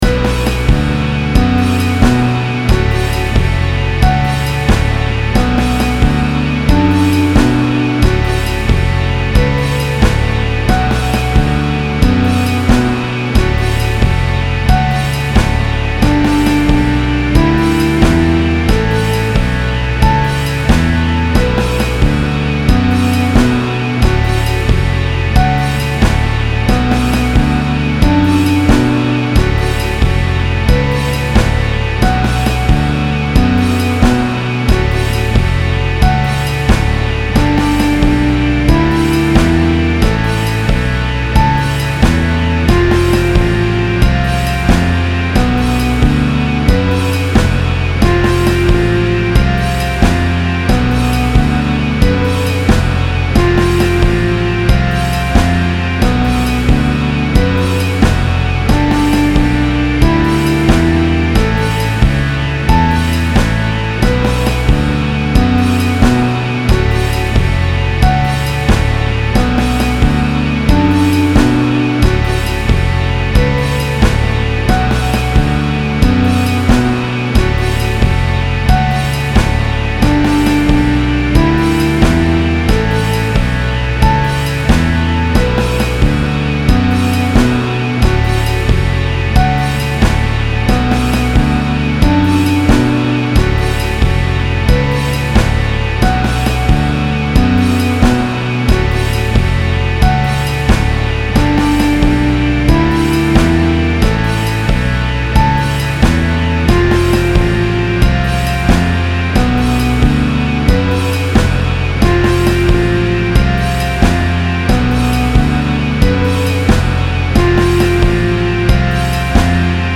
[NOTE: This is a headphone mix, but it is influenced indirectly by the way the new Kustom studio monitors sound . . . ]
-- Basic Rhythm Section with Vibrato Wurlitzer Electric Piano -- MP3
I-Want-To-Dance-With-You-MF3-Vibrato-Wurlitzer.mp3